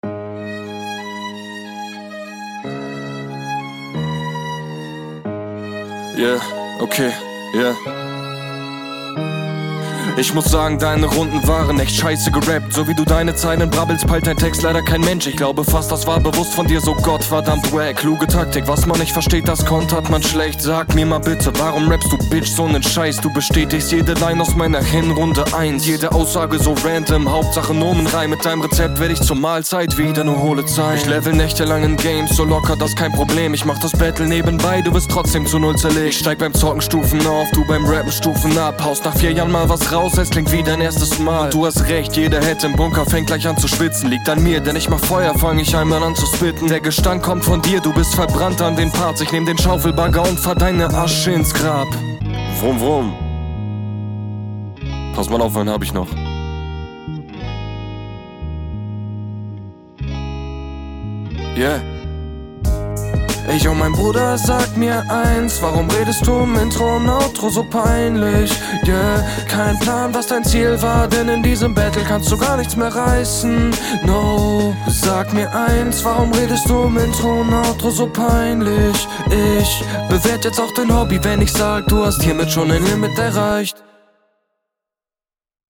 gefällt mir hier im Gesamtbild auch besser außer der Gesang :D